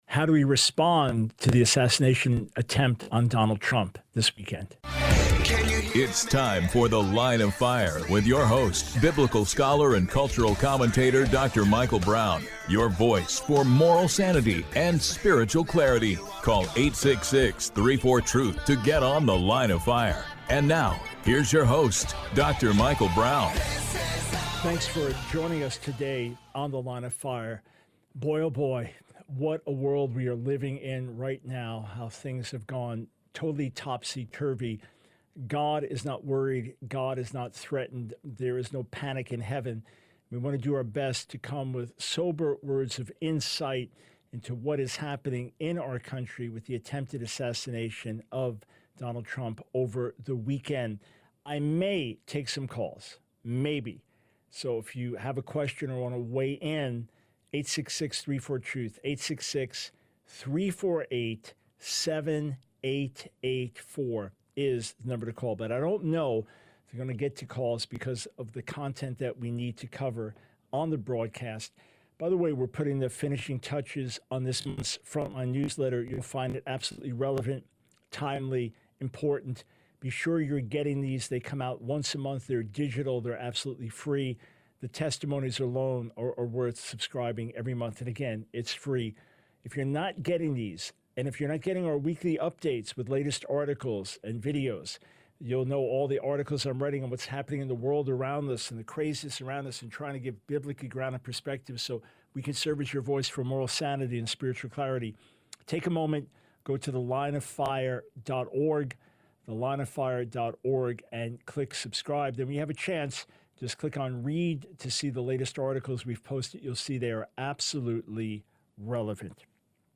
The Line of Fire Radio Broadcast for 07/15/24.